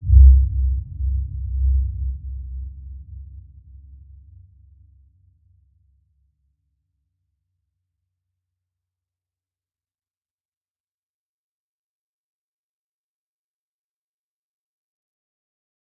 Dark-Soft-Impact-C2-p.wav